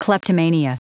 Prononciation audio / Fichier audio de KLEPTOMANIA en anglais
Prononciation du mot : kleptomania